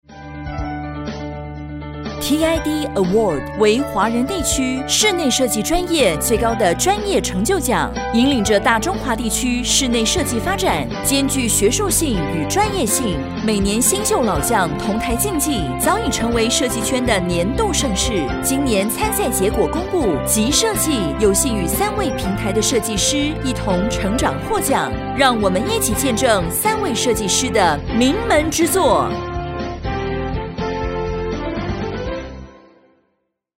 國語配音 女性配音員
她的聲線細緻，能夠以真摯的情感呈現角色內心的複雜性，無論是溫暖的母親形象還是堅韌的女主角，都表現得入木三分。
她能輕鬆駕馭多種語氣，包括溫柔感性、活潑幽默、專業正式，成為各種配音場景的不二之選。